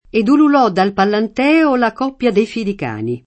Pallanteo [pallant$o] top. m. — la leggendaria città fondata sul Palatino da Evandro: Ed ululò dal Pallantèo la coppia Dei fidi cani [